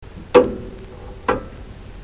cartoon35.mp3